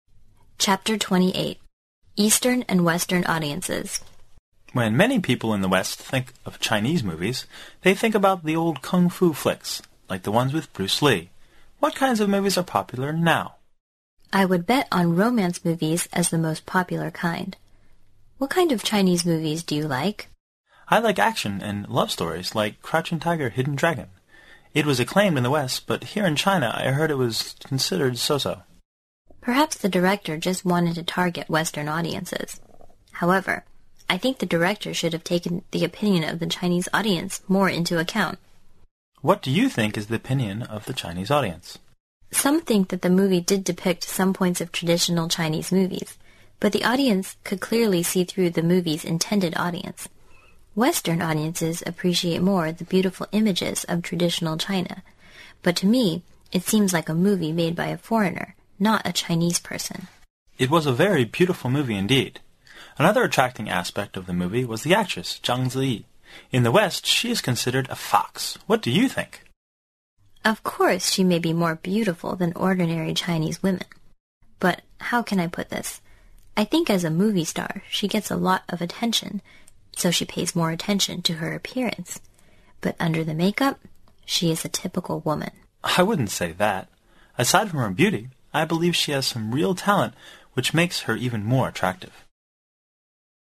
原汁原味的语言素材，习得口语的最佳语境。